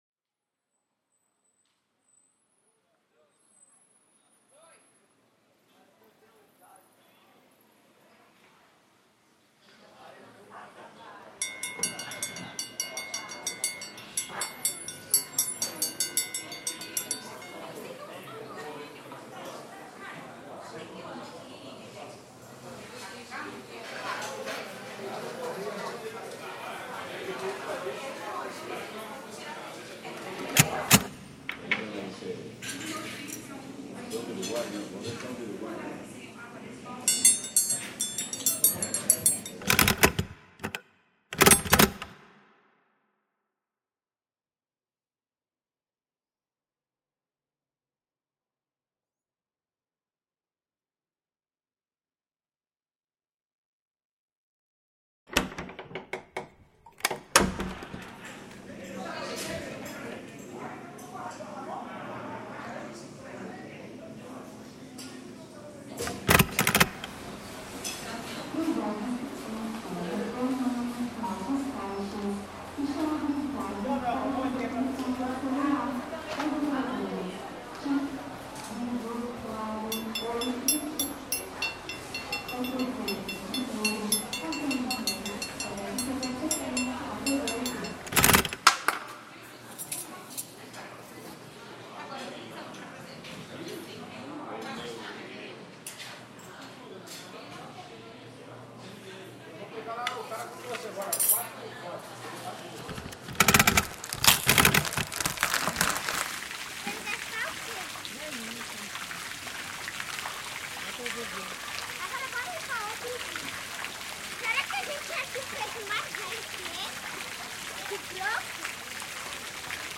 musique anecdotique
All of those sounds, including Summira 7, have similar morphologies, which favors the assemblage. The piece compositional method was montage.